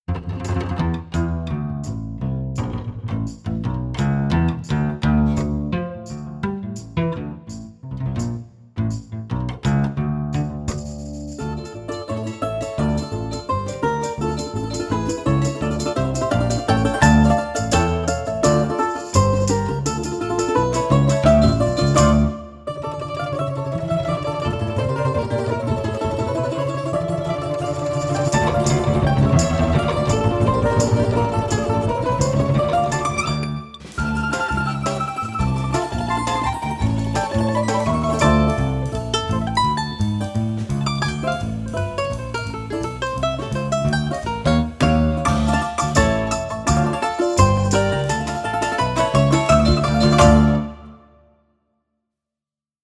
Folk Song